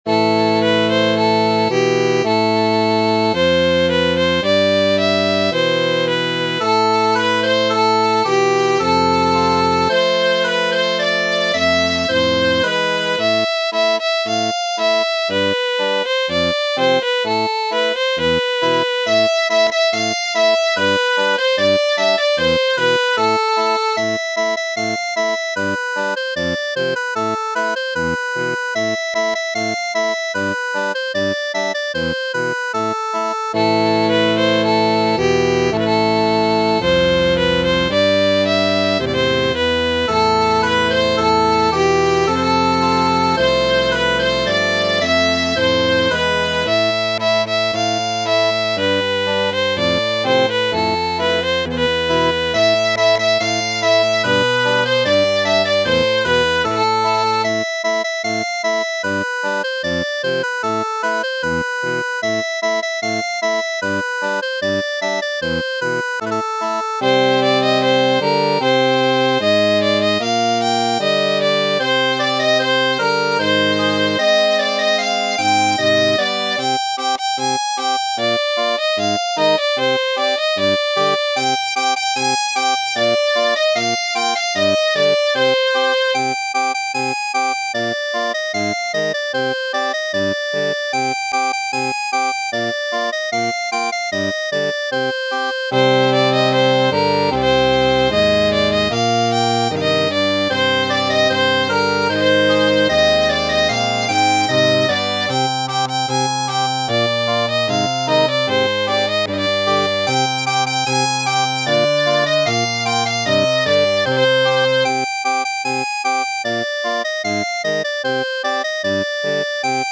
- Ton 3: Erik Marchand et Thierry Robin (réinterprétation utilisant les ressources de la musique orientale).
Kanet gant Erik Marchand